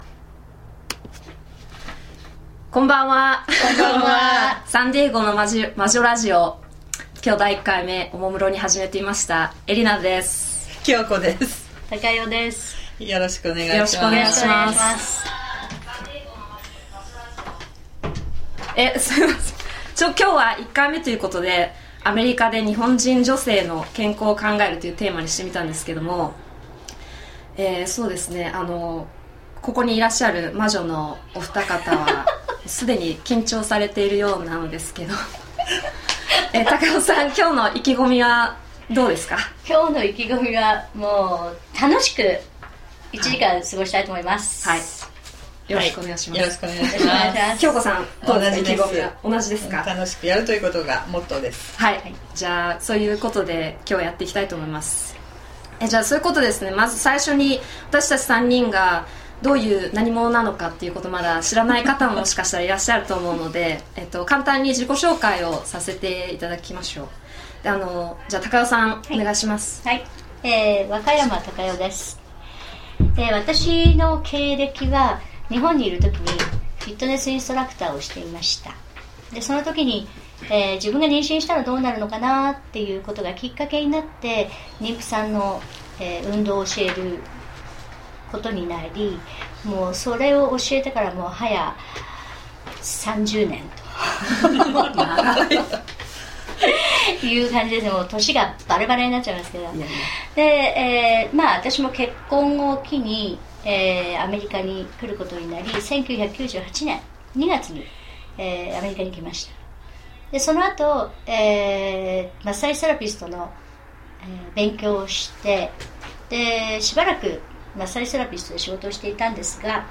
ちょうど一ヶ月前、ustreamを使って、ここサンディエゴからラジオ放送をしてみました。